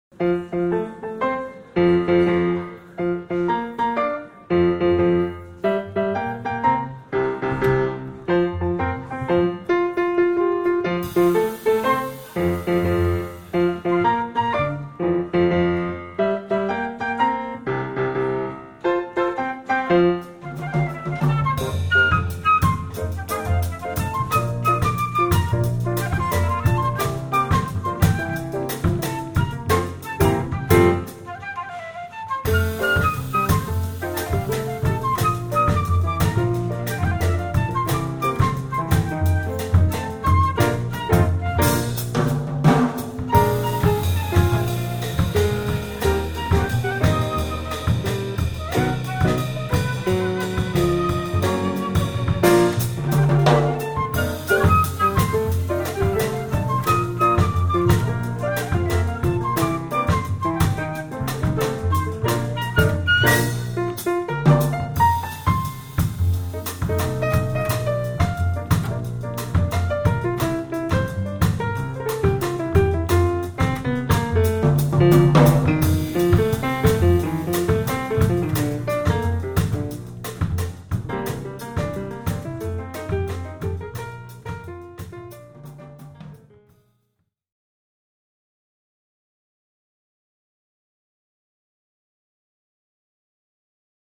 Here are some clips from recent jazz performances: